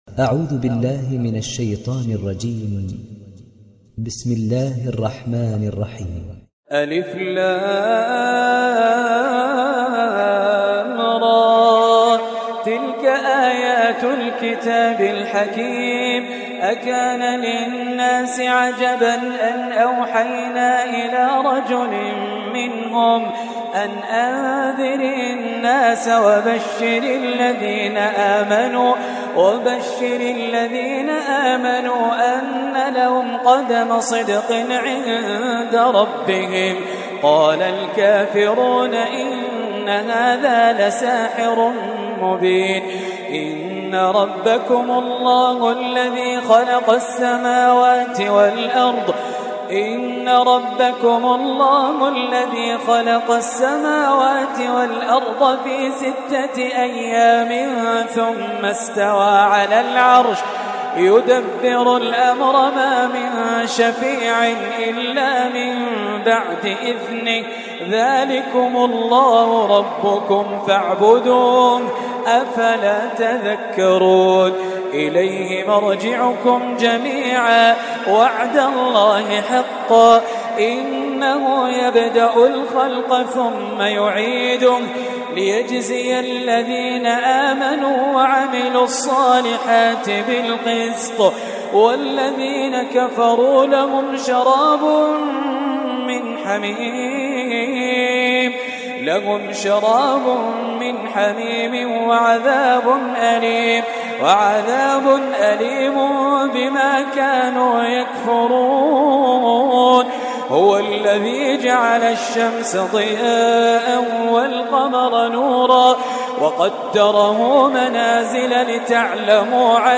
دانلود سوره يونس mp3 خالد الجليل روایت حفص از عاصم, قرآن را دانلود کنید و گوش کن mp3 ، لینک مستقیم کامل